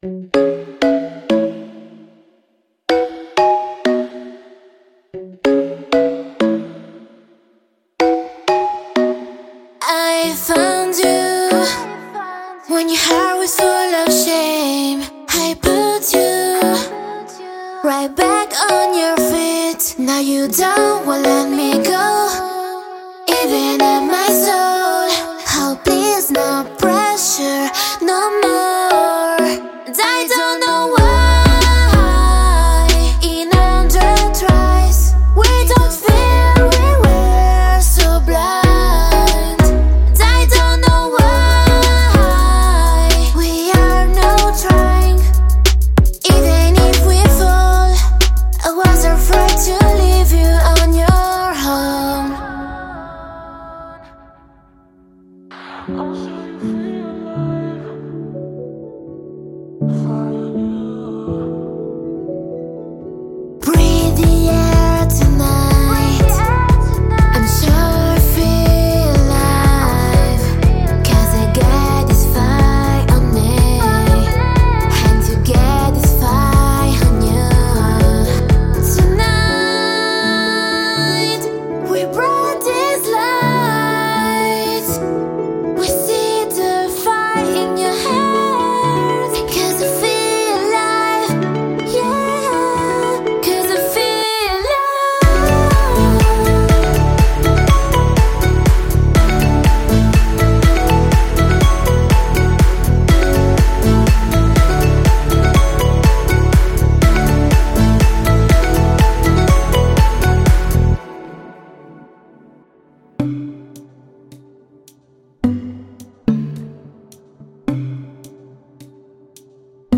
请注意：此包仅包含人声。音乐和节奏元素仅用于演示目的。
•8个呼喊
•88 – 162 bpm